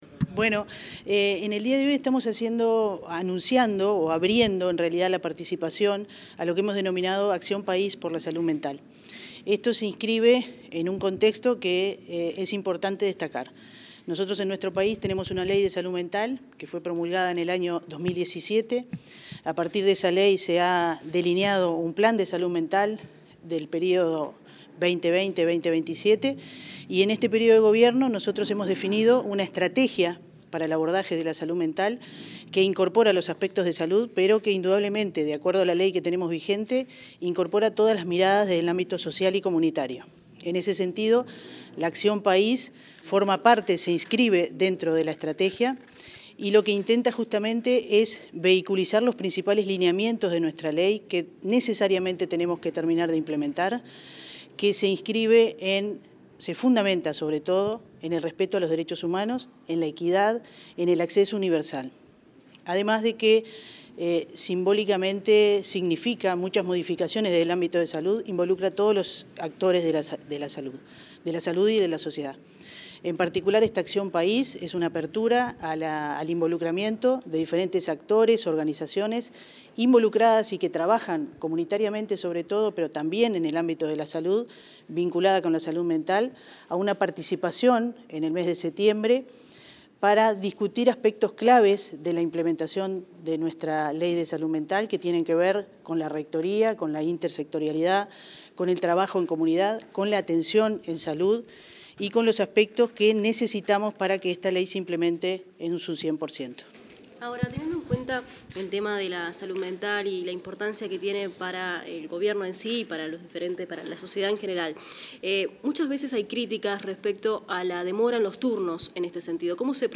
Declaraciones de la directora general de Salud, Fernanda Nozar